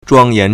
庄严 (莊嚴) zhuāngyán
zhuang1yan2.mp3